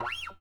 OVATION FX1.wav